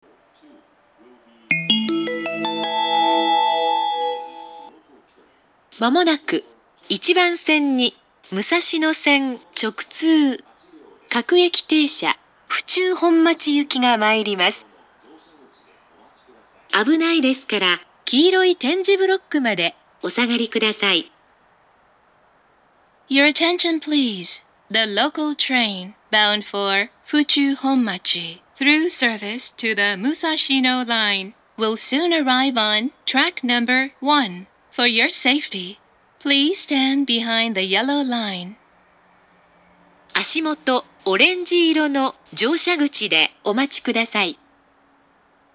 １番線接近放送
１番線到着放送
発車メロディー（ＪＲＥ-ＩＫＳＴ-０0１-0２）
利用客が多いため、余韻までは比較的鳴りやすいです。
shinkiba1bansen-sekkin3.mp3